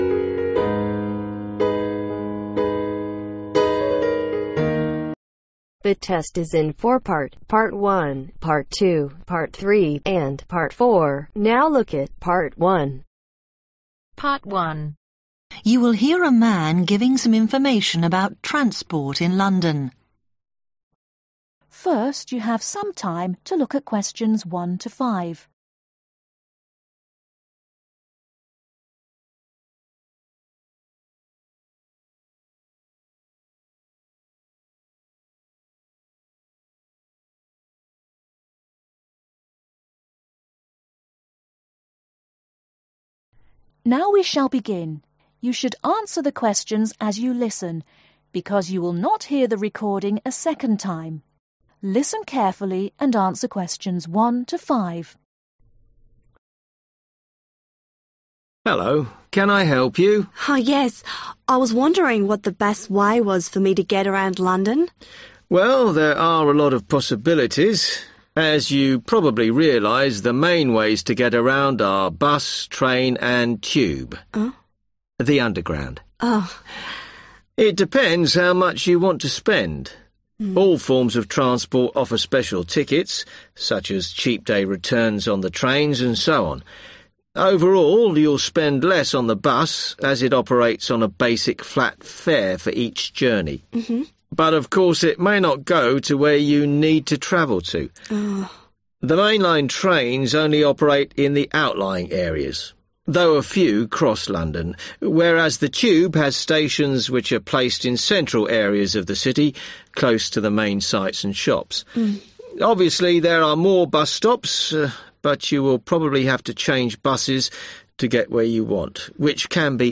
Practice test listening ielts with answers